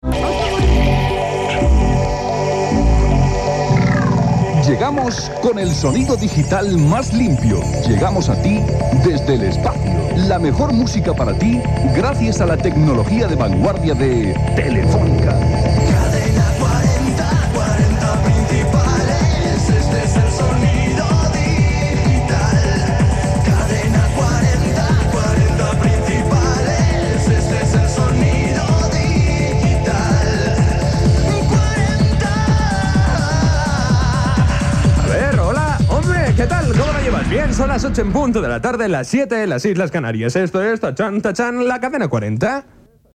Indicatiu emissió digital